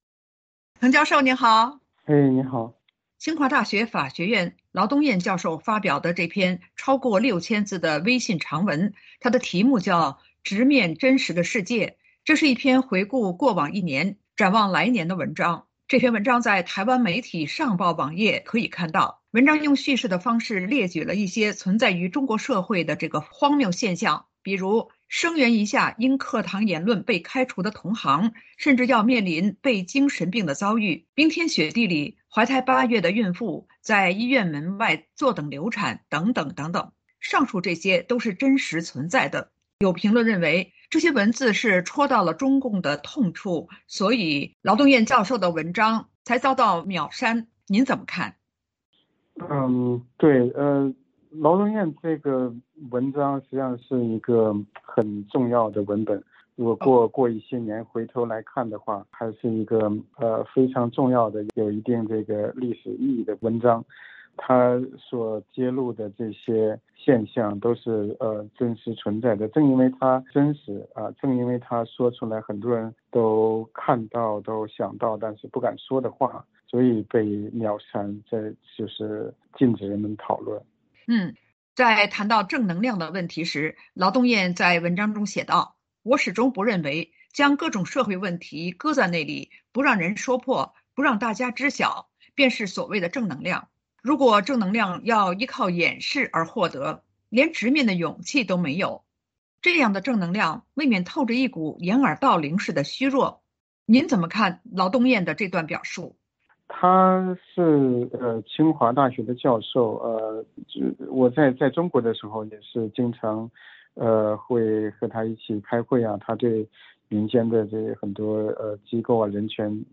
VOA连线：劳东燕网文因“违规”遭“秒删”滕彪：她只是说了真话